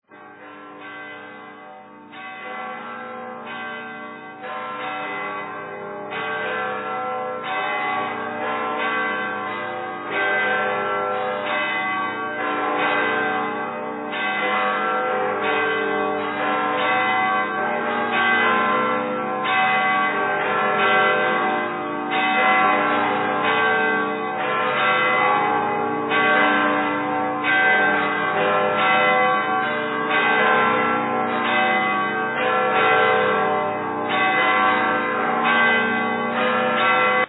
Bowed Cymbal, Trombone